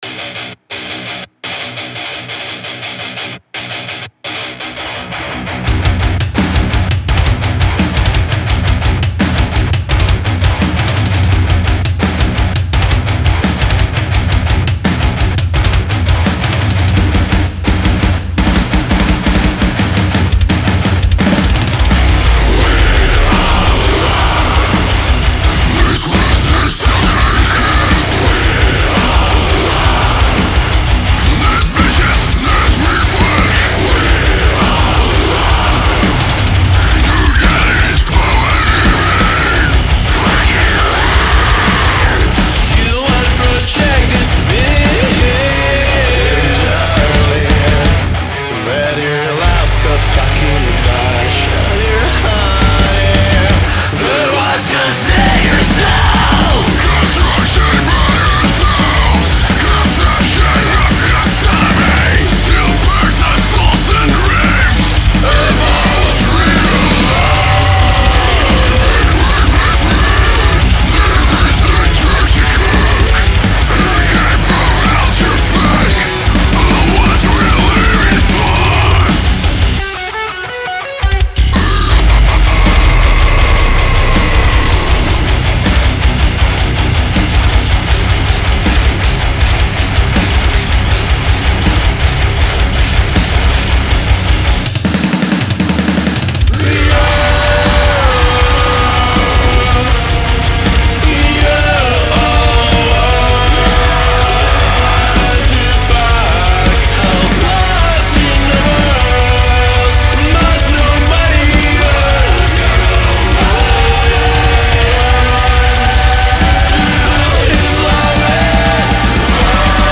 New band i've mixed so far (metalcore/deathcore)
So here it is: This band has really brutal vocals though Better quality here...